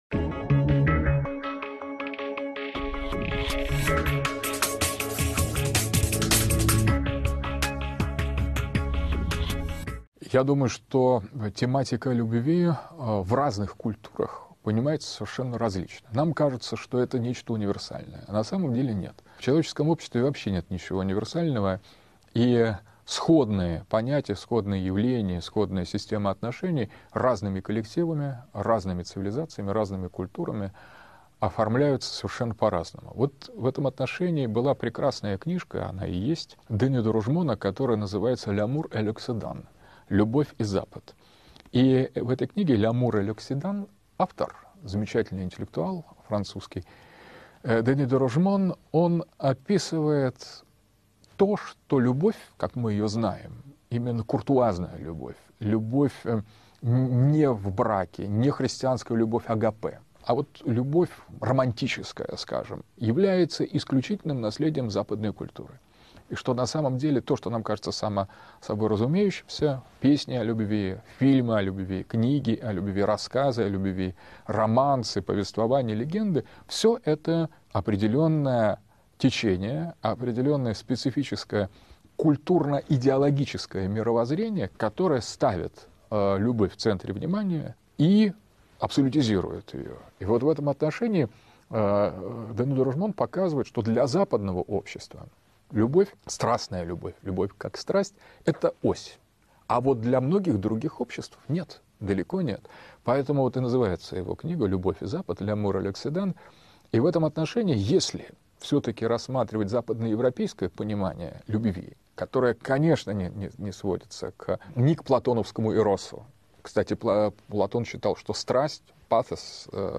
Как менялось понятие любви в истории человечества? К чему пришла любовь в европейской культуре, и что поставило её существование под угрозу? Рассказывает философ Александр Дугин.